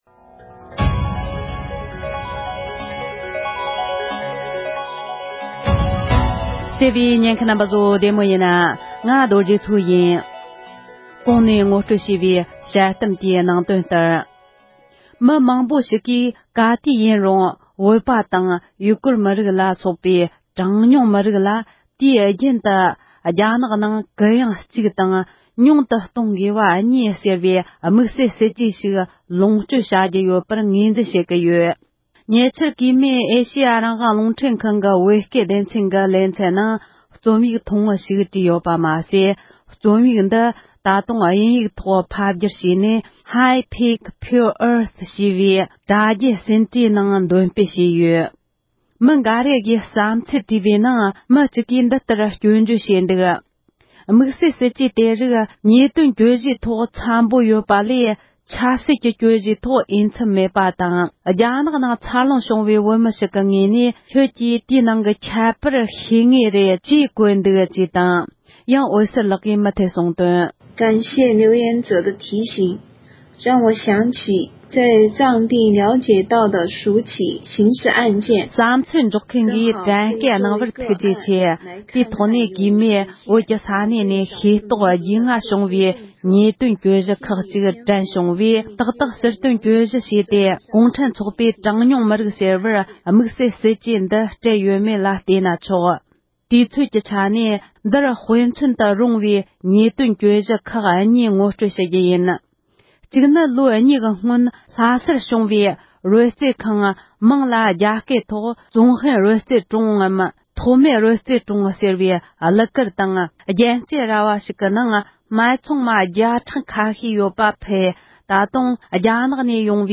འོད་ཟེར་ལགས་ཀྱི་དཔྱད་གཏམ།